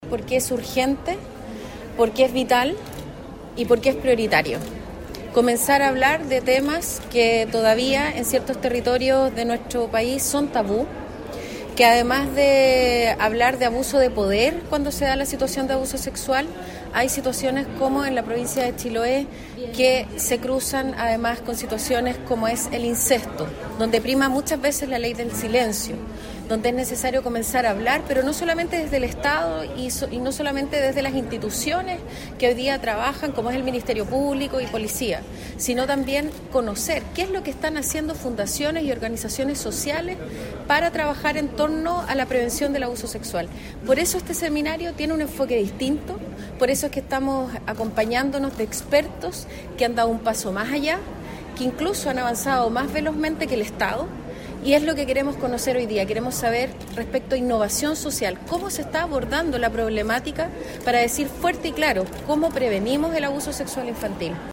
En Castro se realizó el seminario denominado “El Peor Abuso”